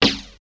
99 SNARE 4-R.wav